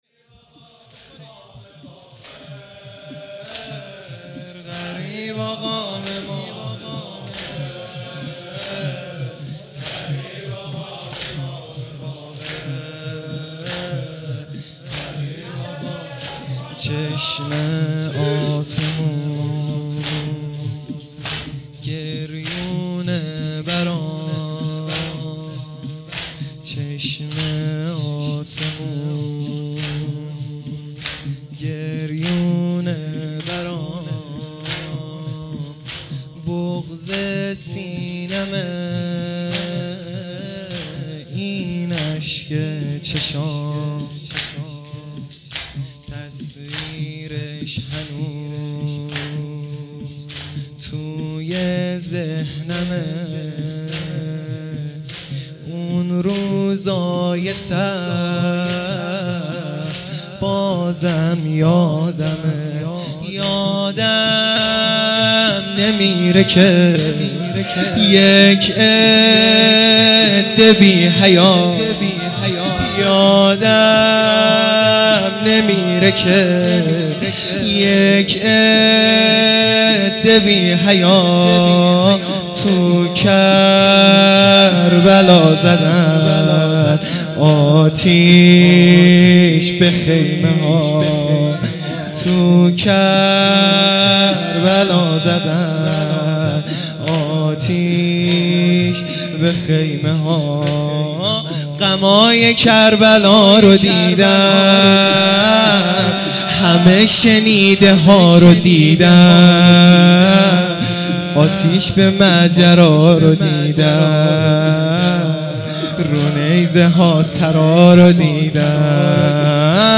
شهادت امام باقر علیه السلام